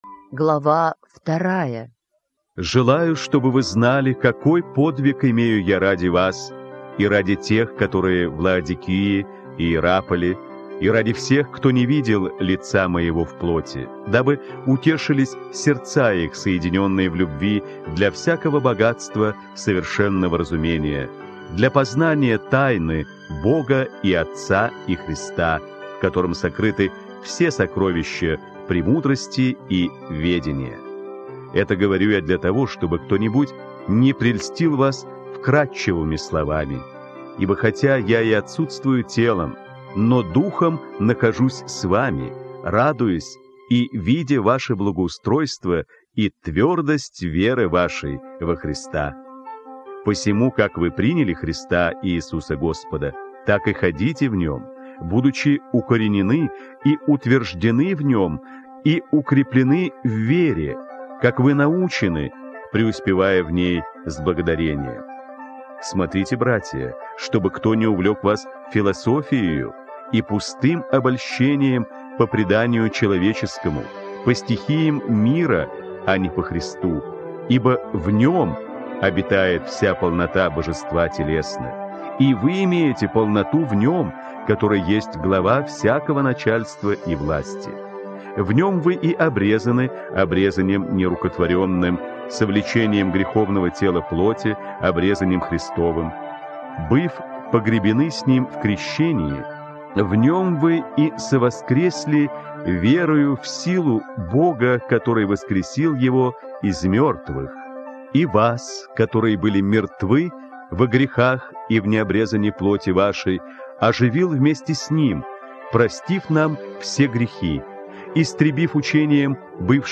Послание к Колоссянам - Аудио Библия онлайн
• Глава 1, Послание к Колоссянам - По ролям - студия «Свет на Востоке».